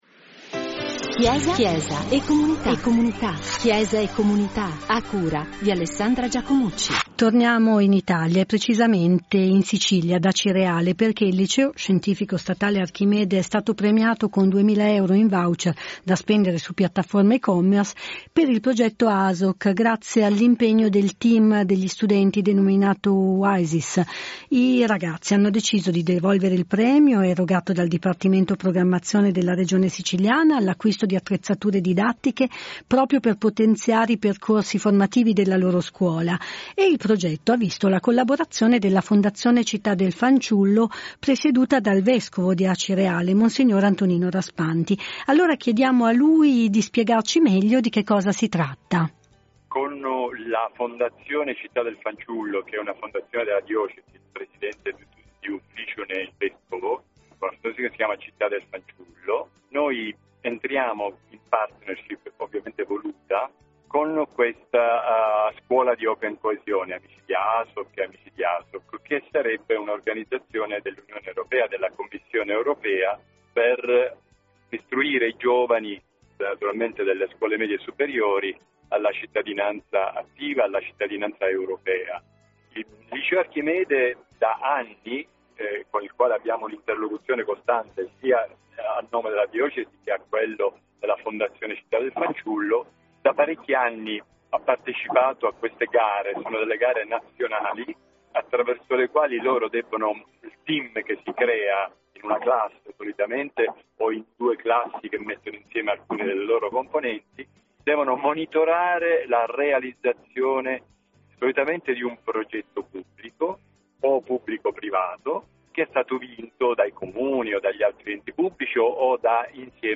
Ascolta l’Intervista del vescovo mons. Antonino Raspanti